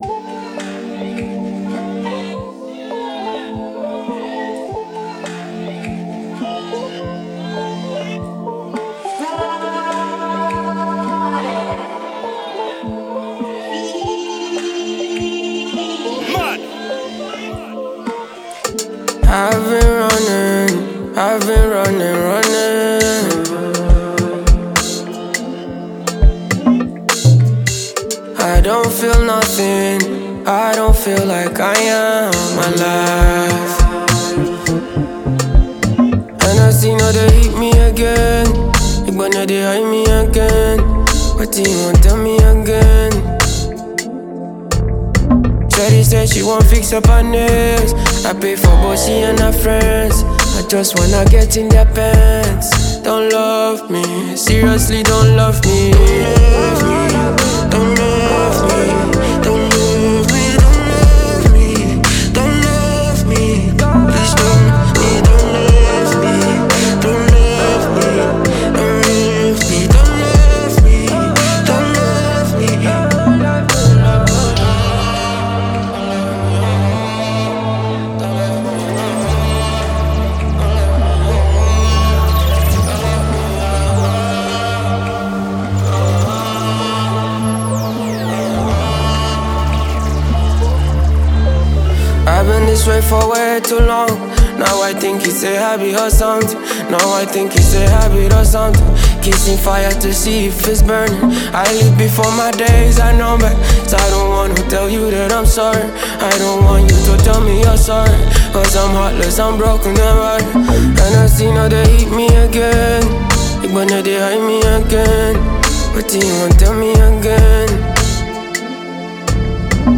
With a voice that’s raw and honest